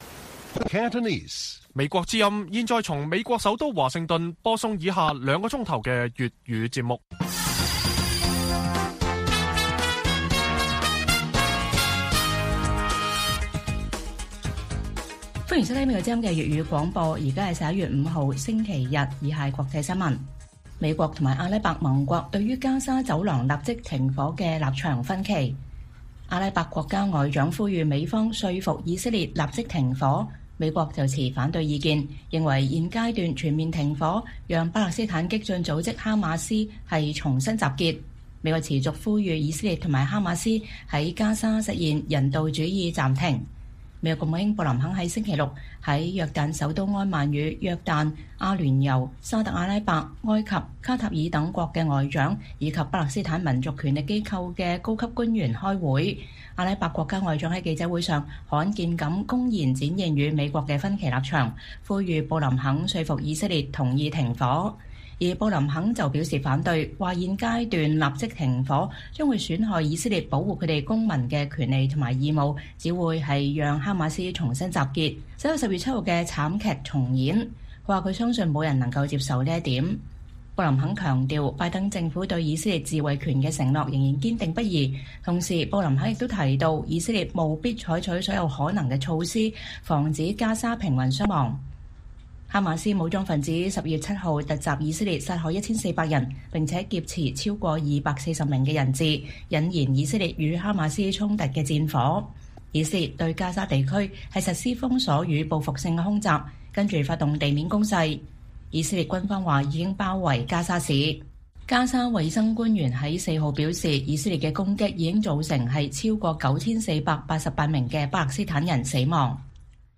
粵語新聞 晚上9-10點：阿拉伯盟國與美國立場分歧 布林肯憂全面停火恐讓哈馬斯重新集結